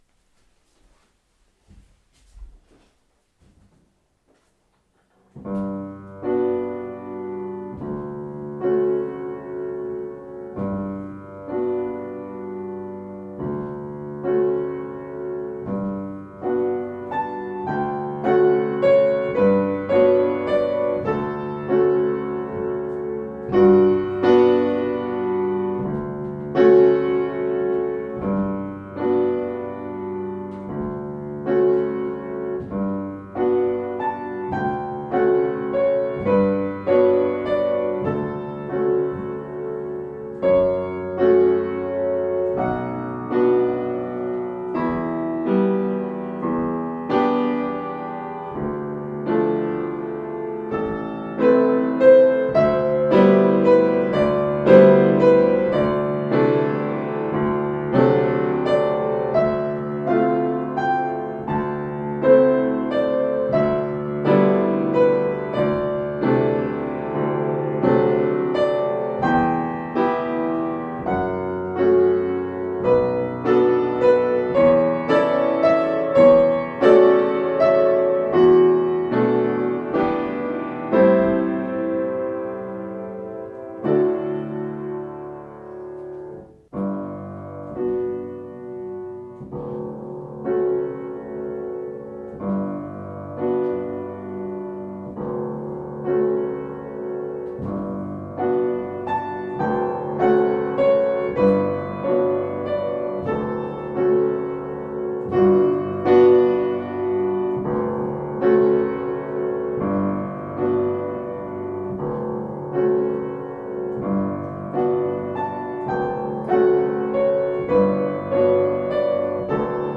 Auch die Interpretation des Stücks als eine Art Wellness-Musik schien mit nicht zur Vortragsanweisung douloureux (schmerzerfüllt) zu passen; denn Satie hatte dabei kaum an Rheuma gedacht. Ich nahm mir vor, das Stück anders zu spielen.